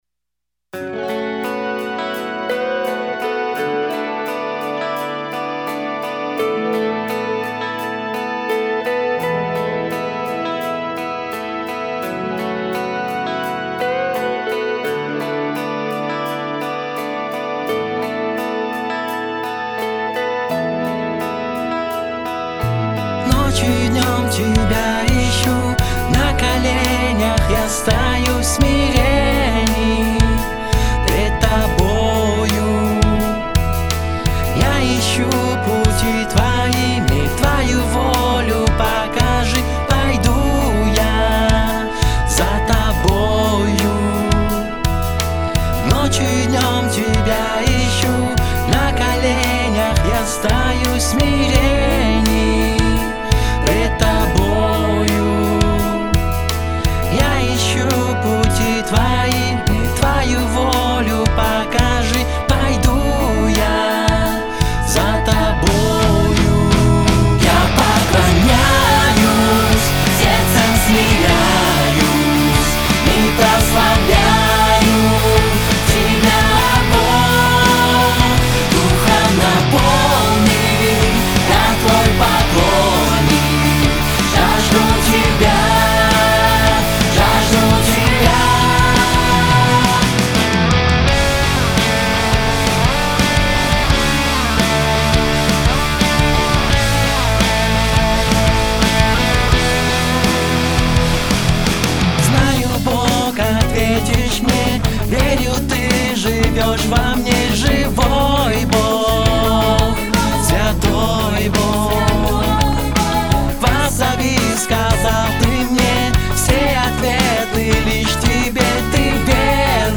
235 просмотров 73 прослушивания 8 скачиваний BPM: 75